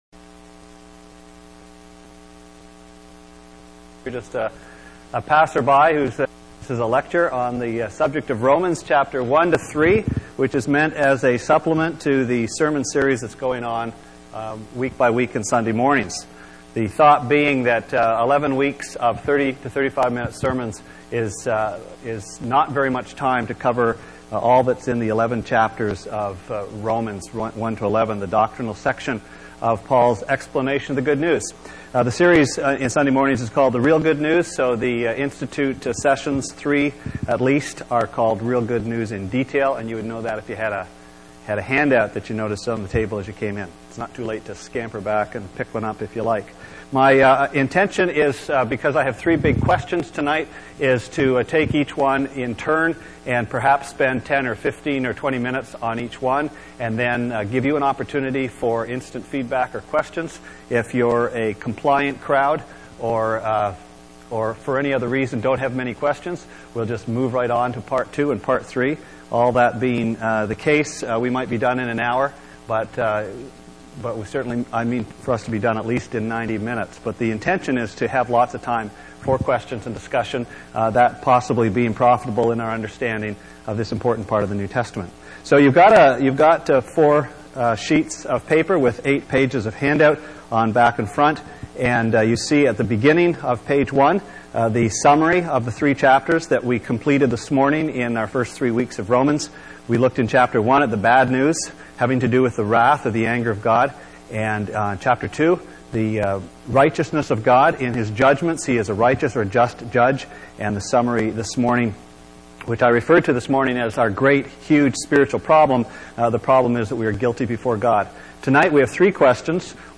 Sermon Archives Jan 22
(Question & Answer period not recorded)